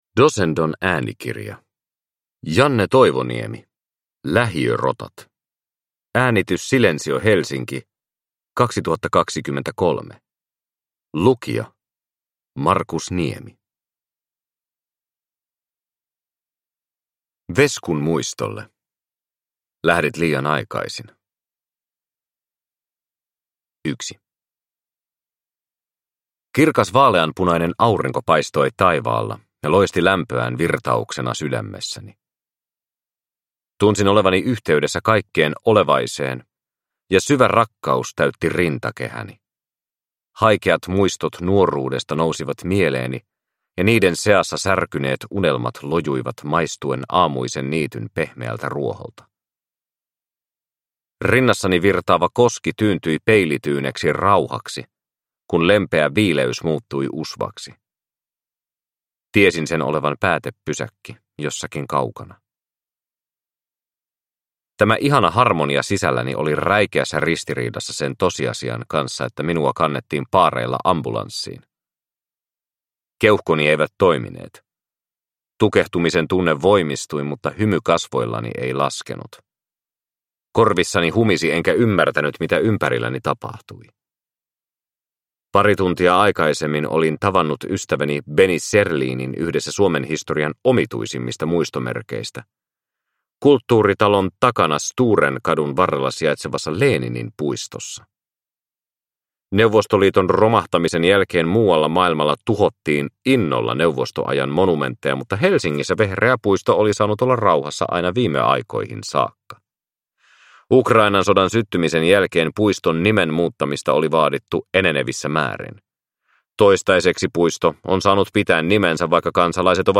Lähiörotat – Ljudbok – Laddas ner